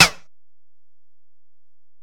Snare (14).wav